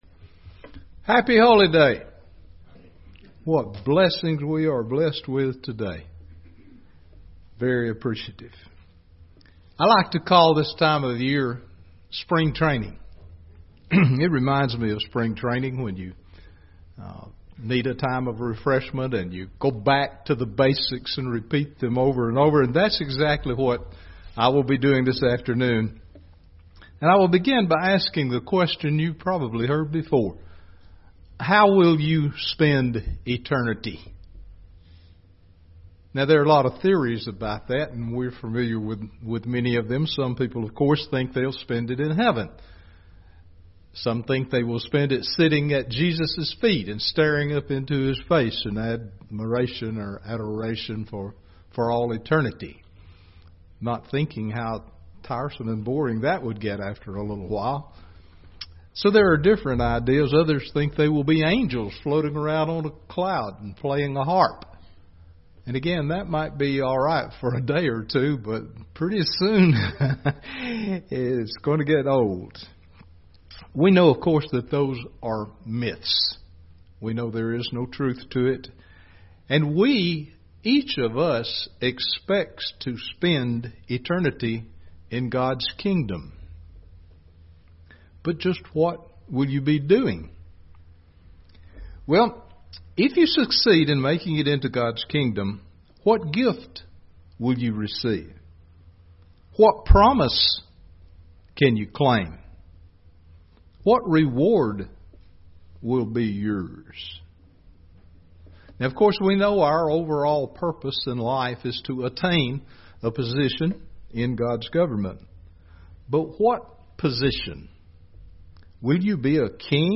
God promises an awesome future for His children! This sermon looks at some of the rewards that awaits the resurrected saints in the Kingdom of God.
Given in Gadsden, AL